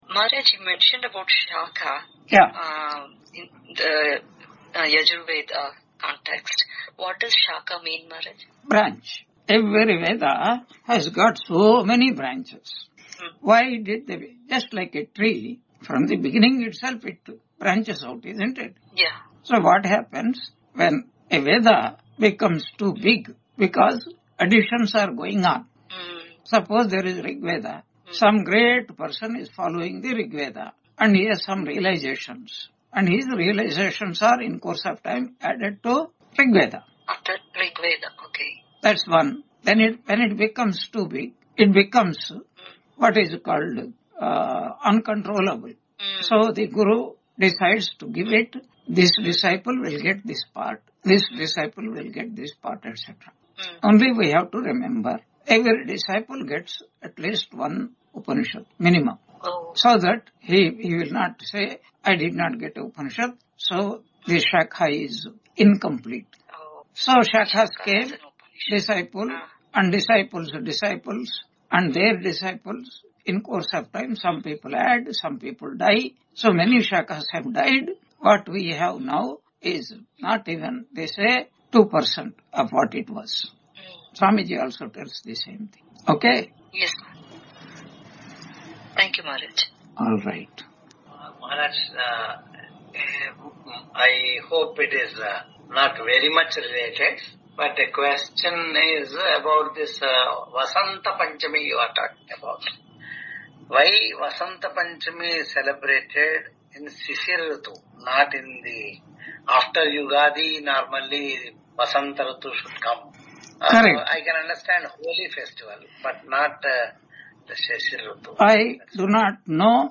Brihadaranyaka Upanishad Introduction Lecture 01 on 24 January 2026 Q&A - Wiki Vedanta